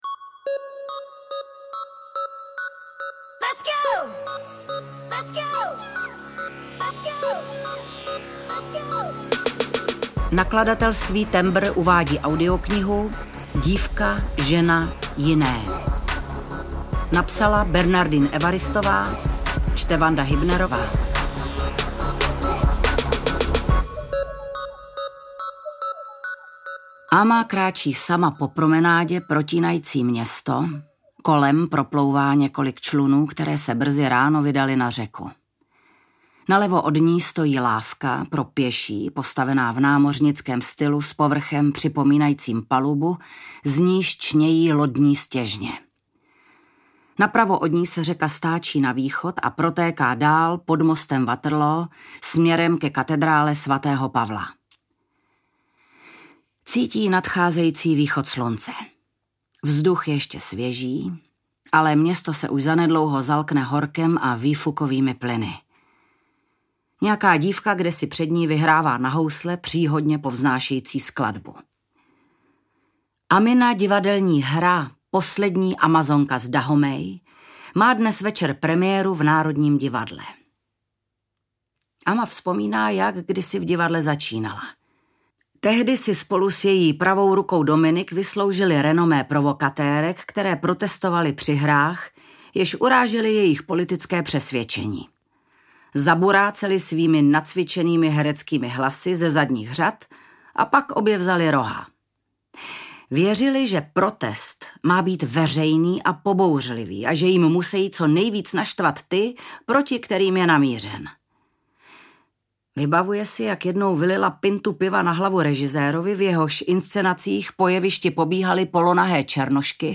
Dívka, žena, jiné audiokniha
Ukázka z knihy
• InterpretVanda Hybnerová